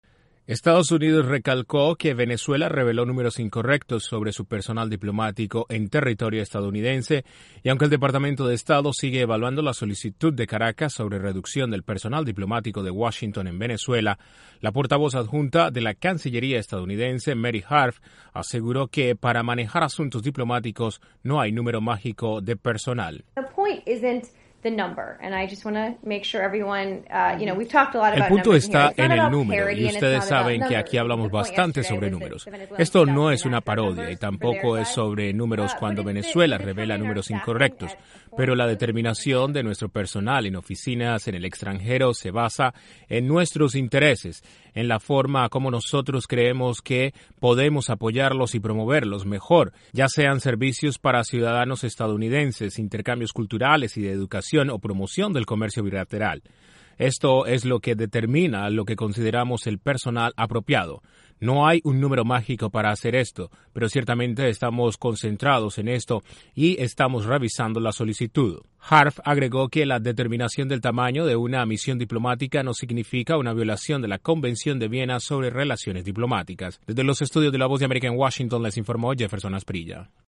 El Departamento de Estado dijo que avalúa solicitud de Venezuela sobre reducción de personal diplomático aunque señaló que no hay un número mágico para determinar tamaño de la misión diplomática. Desde la Voz de América en Washington DC informa